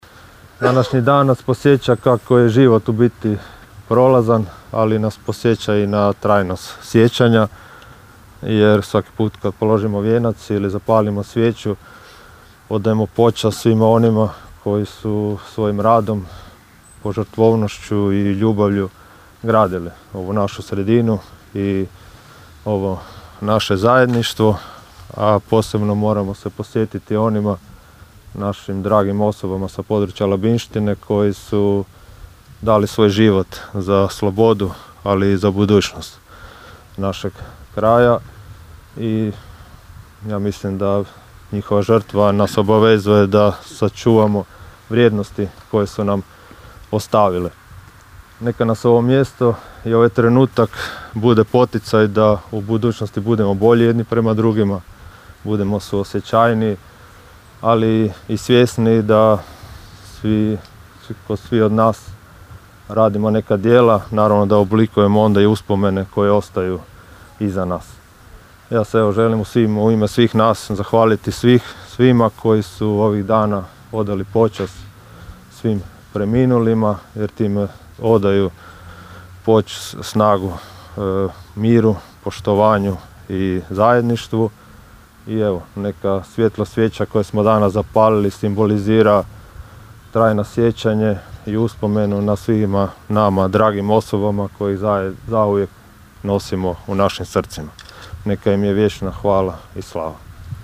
Položen vijenac i zapaljene svijeće na Centralnom križu Gradskog groblja Labin
ton – Donald Blašković).